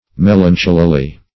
melancholily - definition of melancholily - synonyms, pronunciation, spelling from Free Dictionary Search Result for " melancholily" : The Collaborative International Dictionary of English v.0.48: Melancholily \Mel"an*chol`i*ly\, adv.